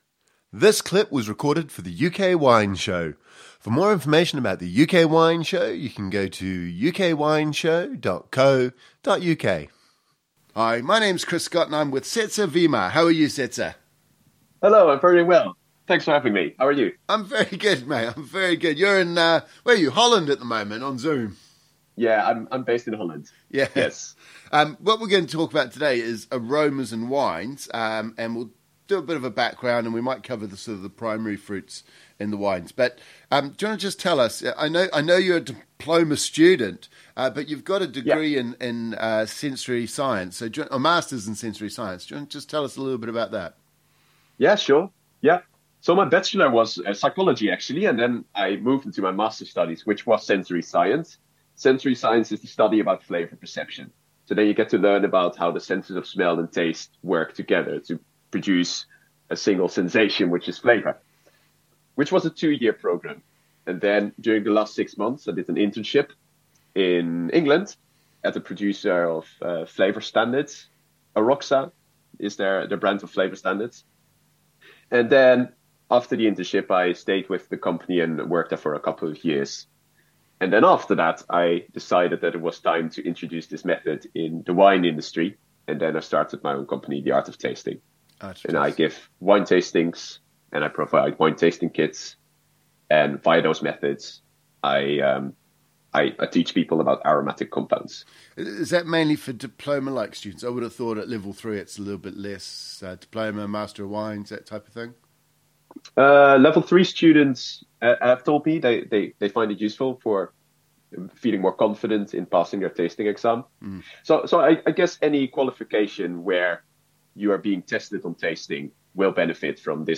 In this first interview, we discuss primary aromas and the 3 categories the aromas come from which are aromas from grapes (if you were to pick in the vineyard), aromas from precursors and aromas derived from winemaking/fermentation.